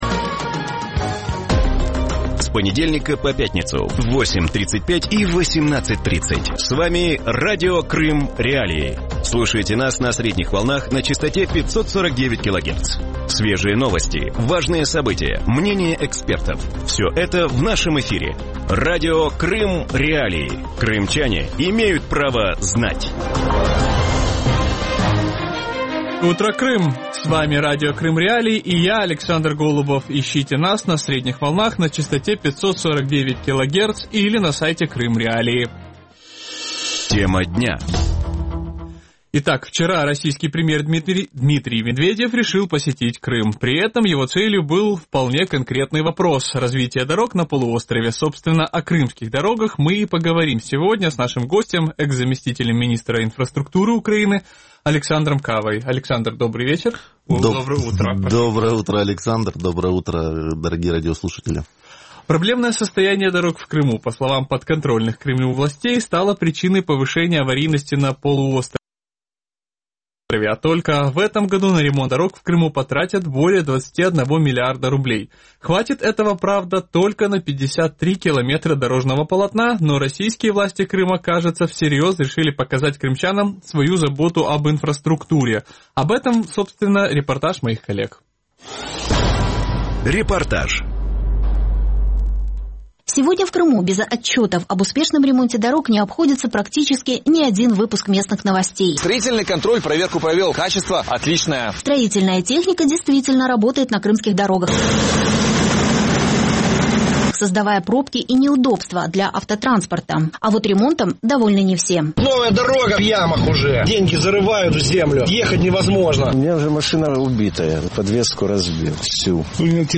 Вранці в ефірі Радіо Крим.Реалії говорять про грандіозні плани російської влади щодо будівництва доріг на півострові. Чи достатньо анонсованих на це видатків?